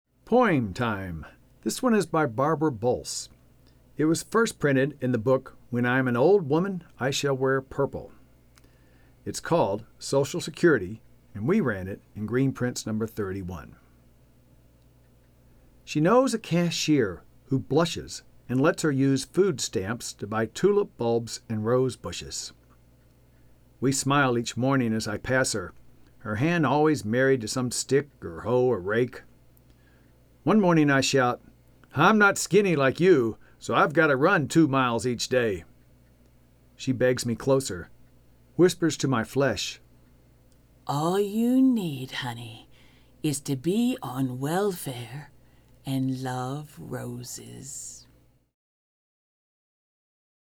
Social Security (Poem